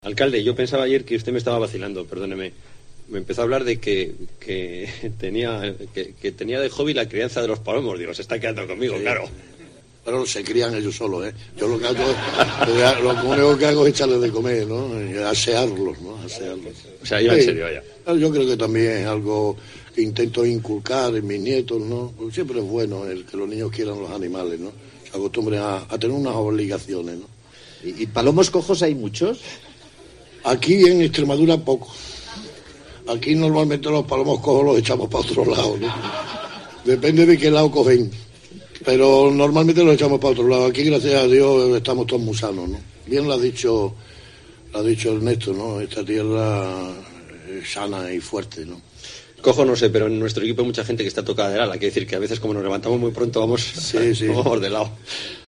Entrevista a Celdrán en La Mañana con Buruaga (2010). Luis del Val le hacía la pregunta: ¿Y (Palomos) Cojos tiene muchos? Escucha la reacción del Alcalde.